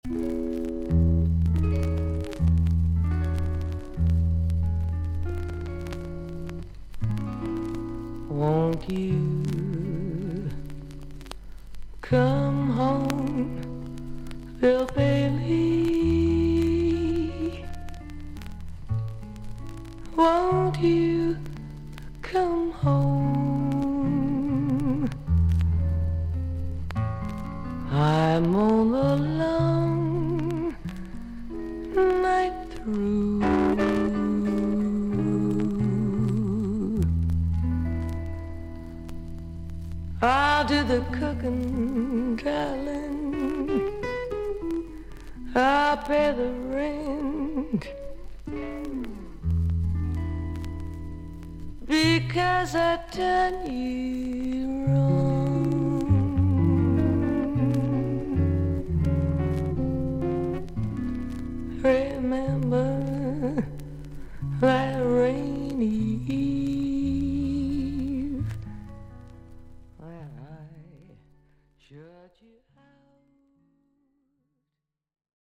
少々サーフィス・ノイズあり。クリアな音です。
セクシーに囁くように歌う女性シンガー。
しっとりと歌ったスタンダード・バラード・アルバムです。